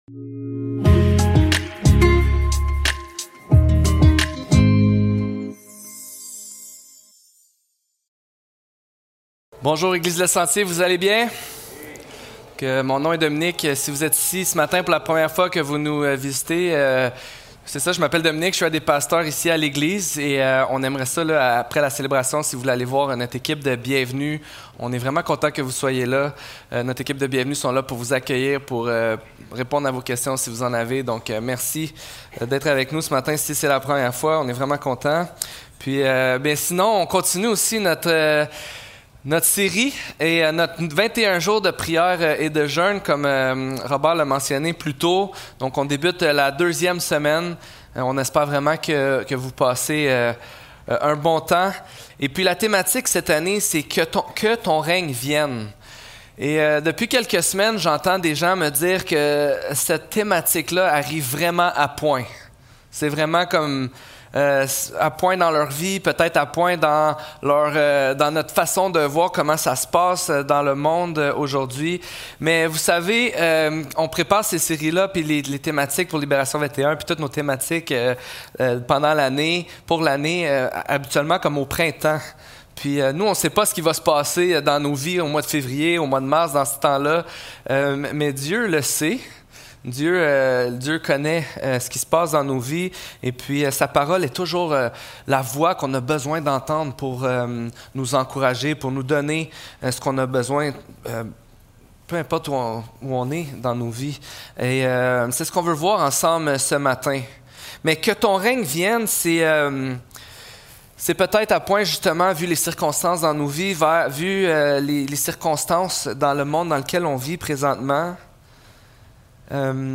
Psaume 29 Service Type: Célébration dimanche matin Description